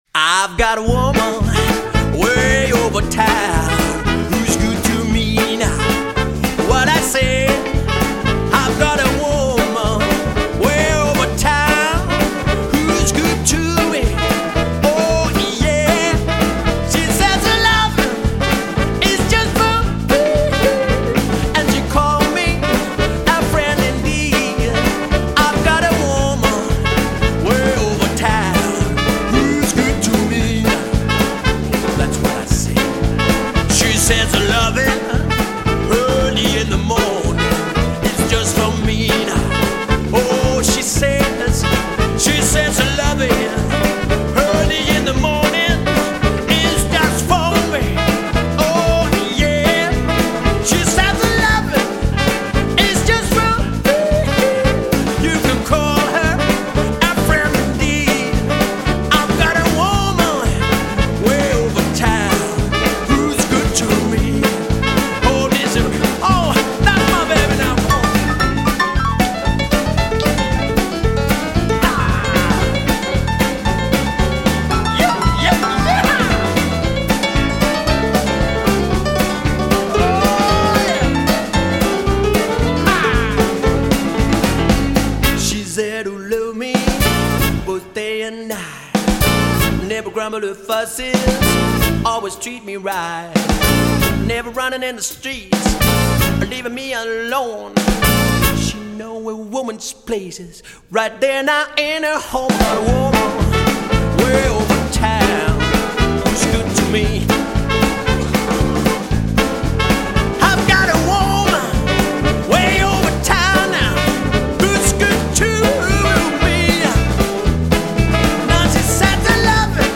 • Coverband
• Bluesband